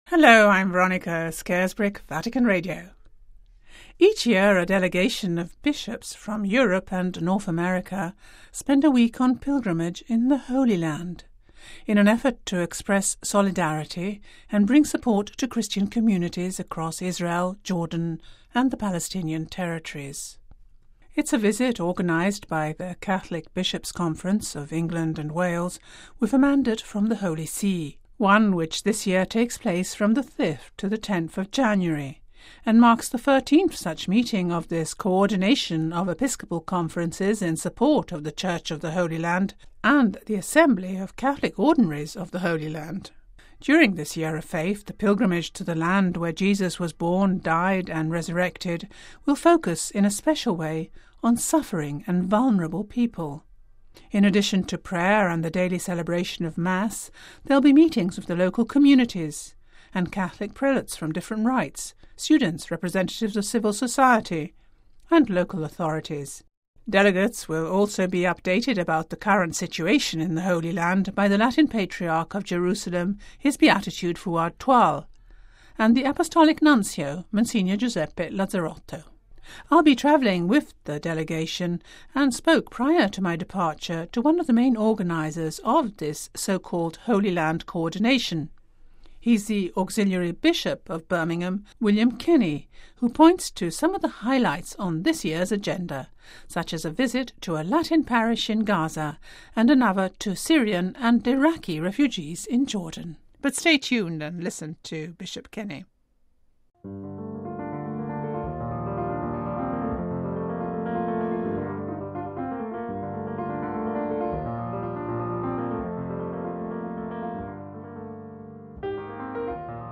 He’s the Auxiliary Bishop of Birmingham, William Kenney who points to some of the highlights on this year's agenda such as a visit to a Latin parish in Gaza and another to Syrian and Iraqi refugees in Jordan.